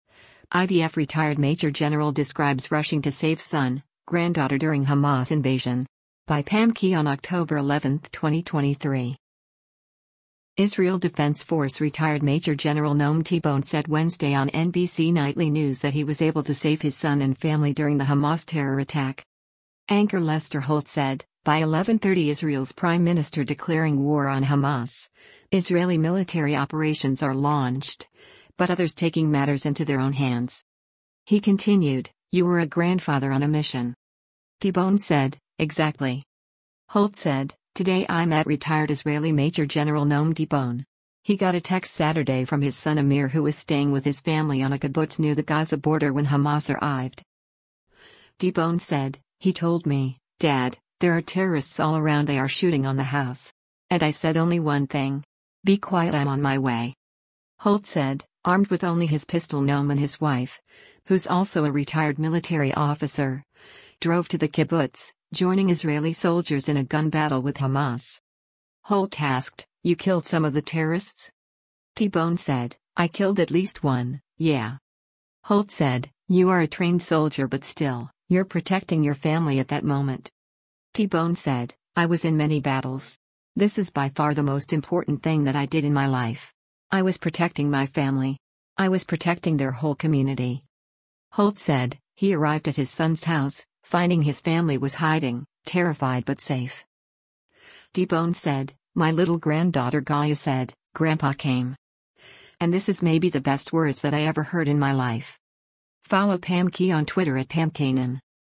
Israel Defense Force retired Major General Noam Tibon said Wednesday on “NBC Nightly News” that he was able to save his son and family during the Hamas terror attack.